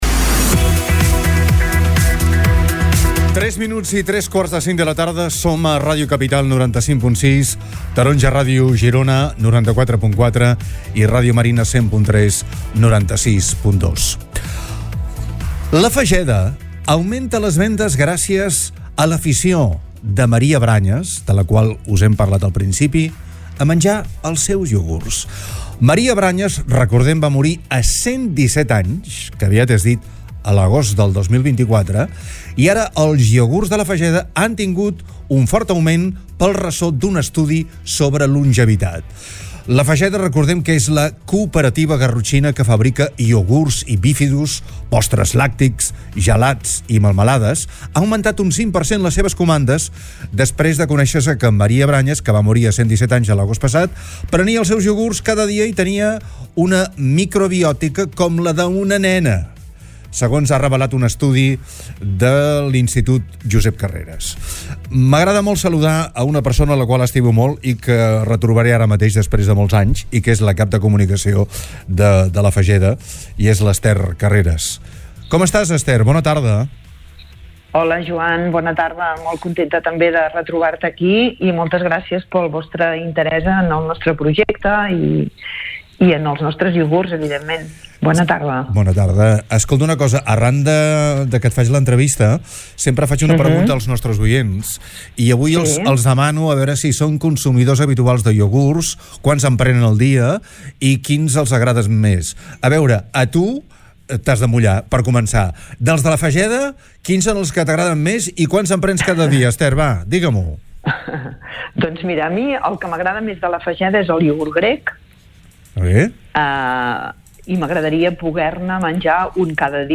En una entrevista al programa DE CAP A CAP